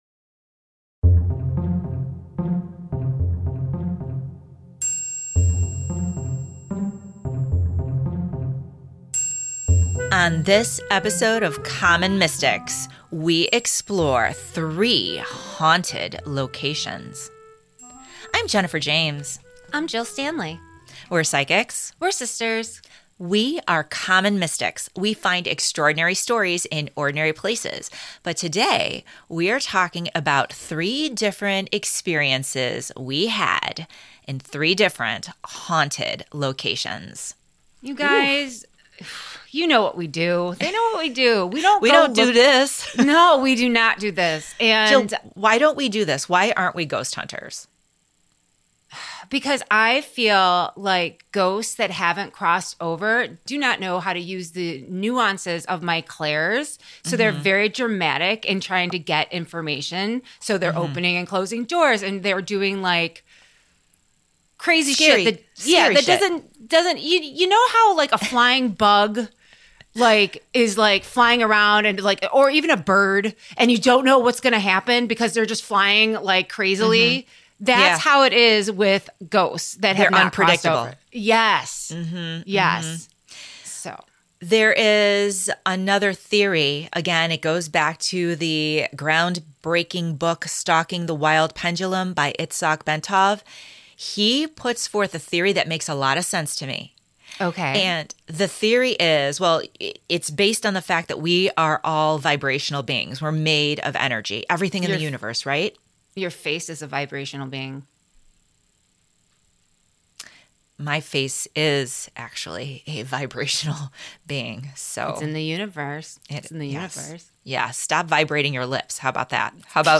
Tune in to experience the incredible audio evidence you have to hear to believe.